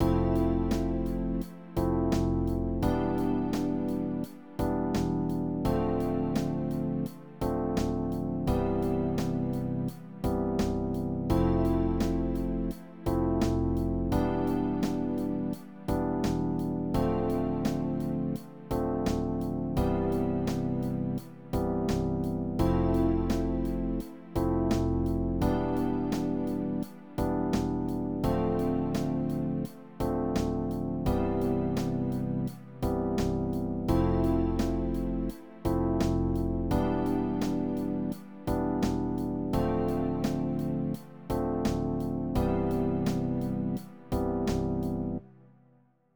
Write a 16-bar lo-fi hip hop beat at 85 BPM with jazzy piano chords, a mellow bass, and vinyl-style drums. Add a Rhodes pad for atmosphere.
Result: 16-bar lo-fi beat with jazzy piano, bass, drums, and atmospheric pad
MidiEditro_AI-lo-fi.wav